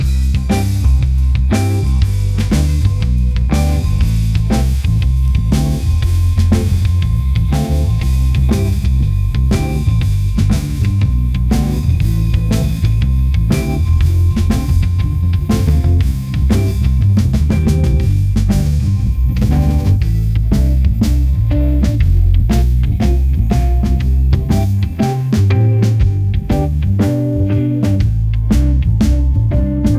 "bpm": 120,
"time_sig": "4/4",
"text_chords": "C G A:min F",